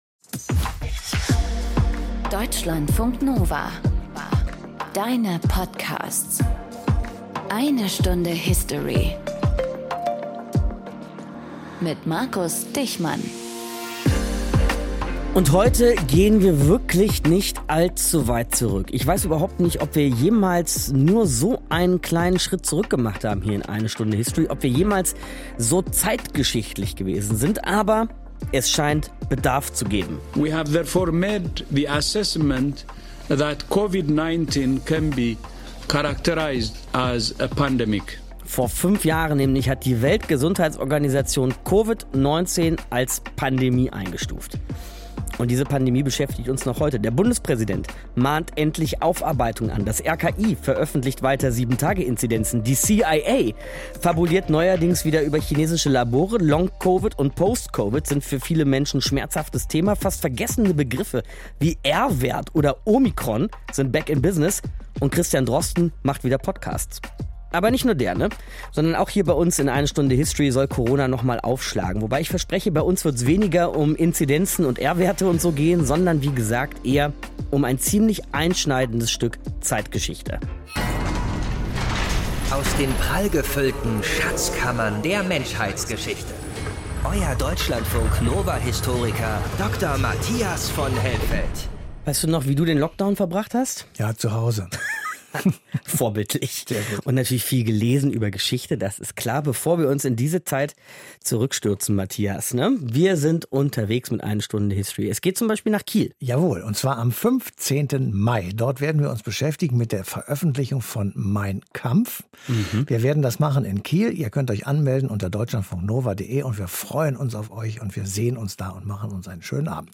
Aus dem Podcast Im Gespräch Podcast abonnieren Podcast hören Podcast Im Gespräch Eine ganze Stunde widmen wir einer Person.